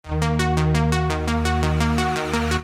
• Качество: 320, Stereo
Sms сообщение